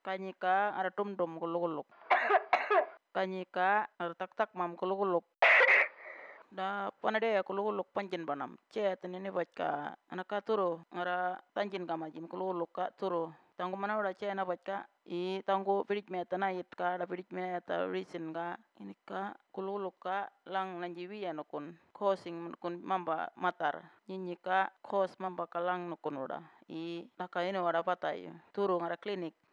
Cough Audio – Murrinh-Patha
20048_murrinh-patha-wet-&-dry-cough-finalc.wav